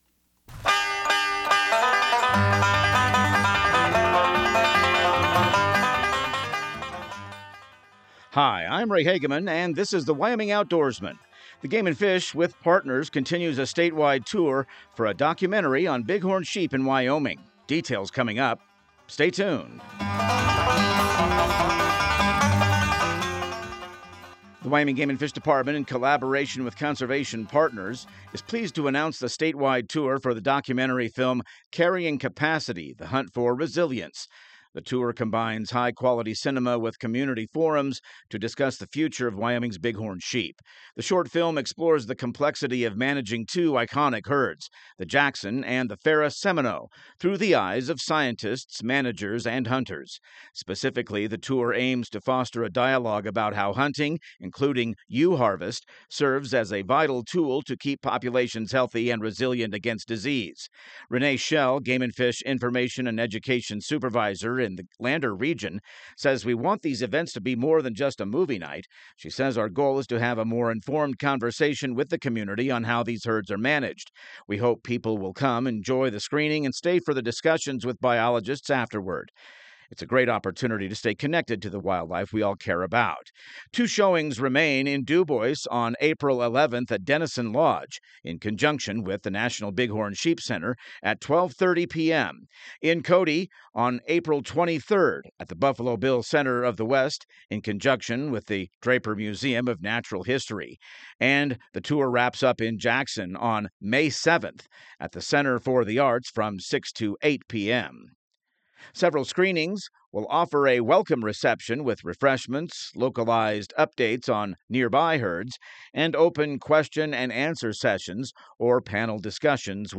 Radio news | Week of March 30